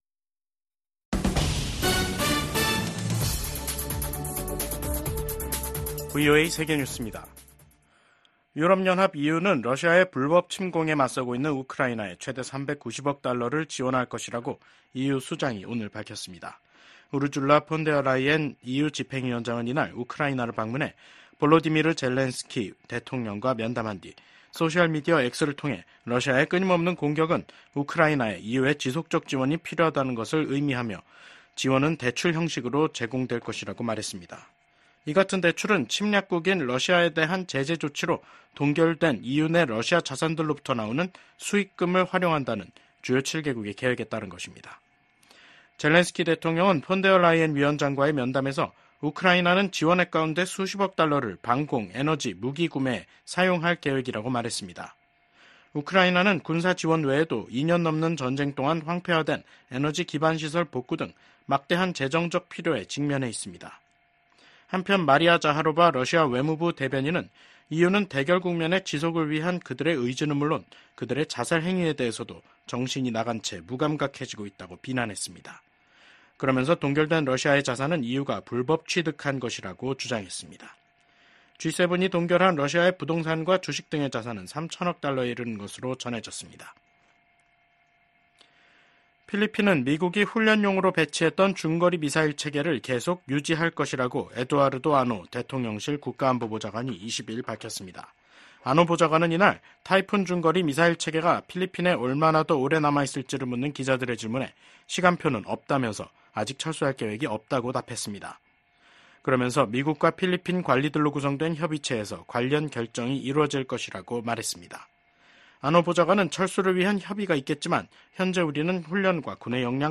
VOA 한국어 간판 뉴스 프로그램 '뉴스 투데이', 2024년 9월 20일 3부 방송입니다. 미국 정부가 북-러 간 불법 자금 거래에 관여한 러시아 회사 5곳 및 국적자 1명을 전격 제재했습니다.